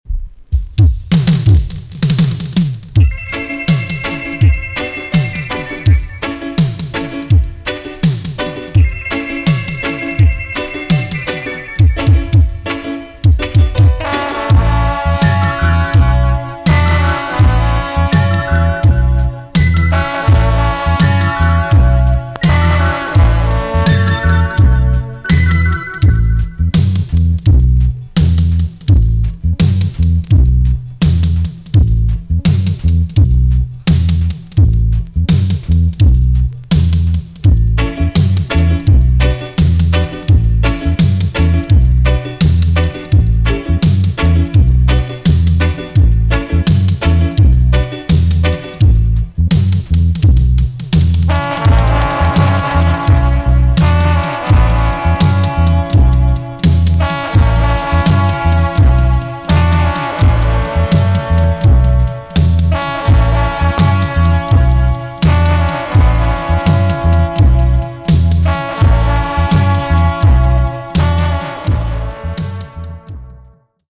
TOP >DISCO45 >80'S 90'S DANCEHALL
B.SIDE Version
EX- 音はキレイです。